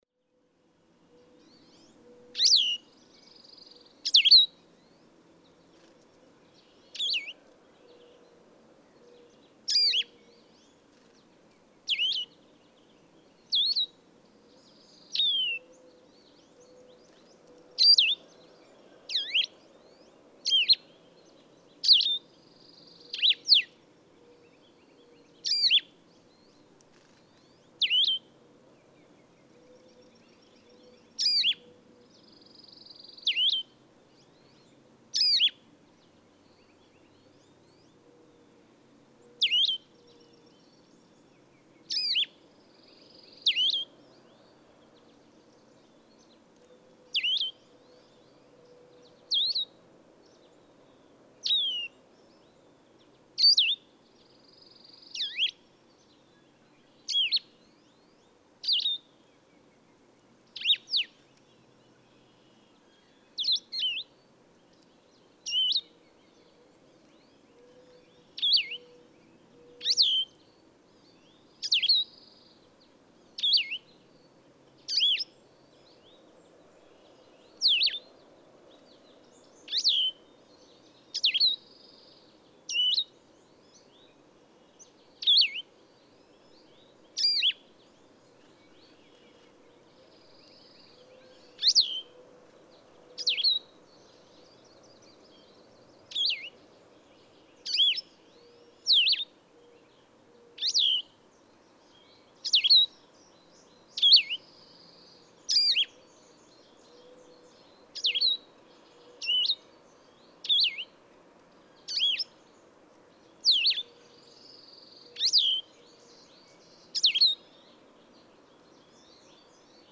Blue-headed vireo